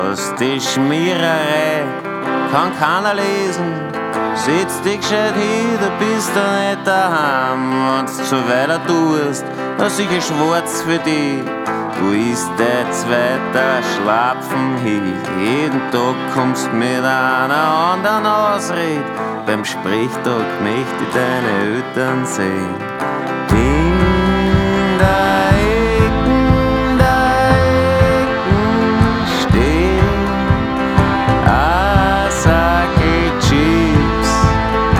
# Инди-поп